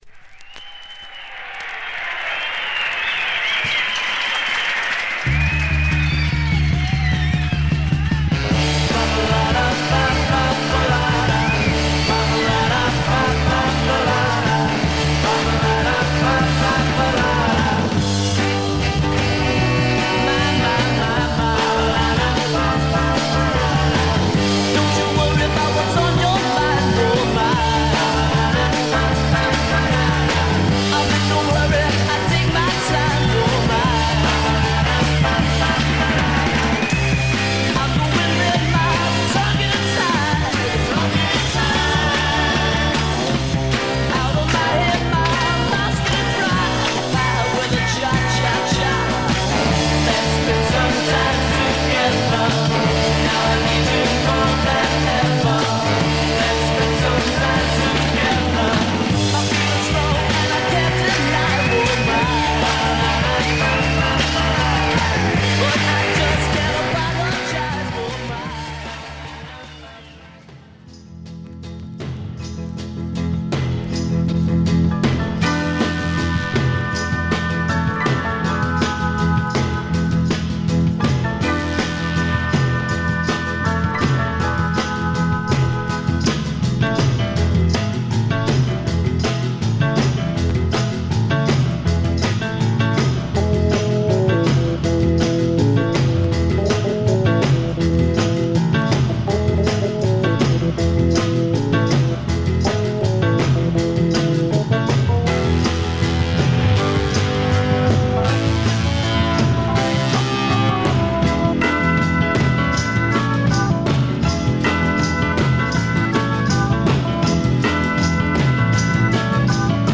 Stereo/mono Mono
Rock